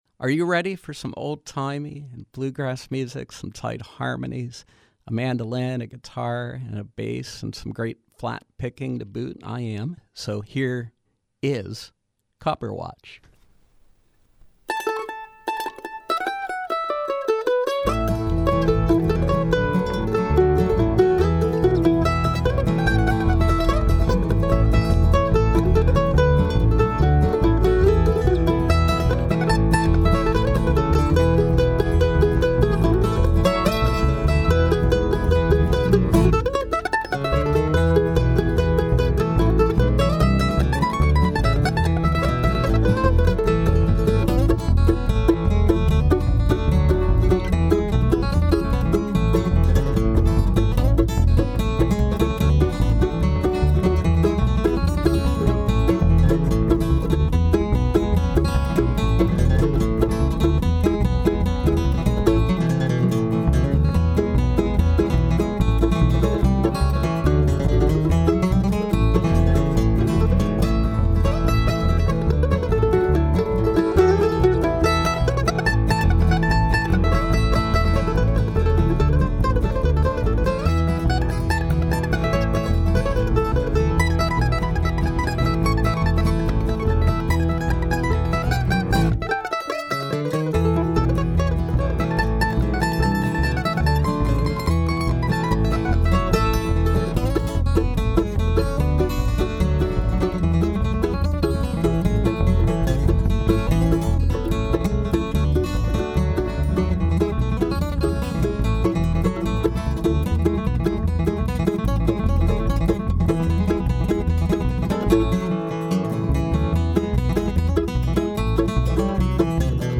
Live music from bluegrass trio
mandolin
guitar
bass